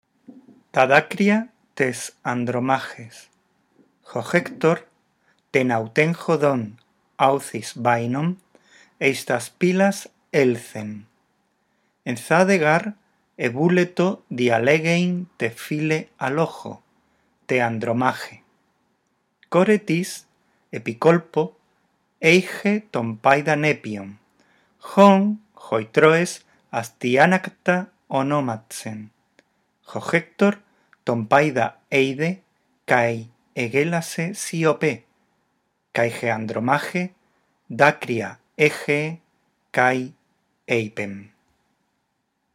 Lee el texto en voz alta, respetando los signos de puntuación. Después escucha estos tres archivos de audio y repite la lectura.